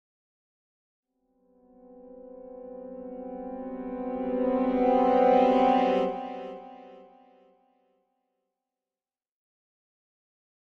Symphonic Strings Suspense Chord 2 - Increasing - Lower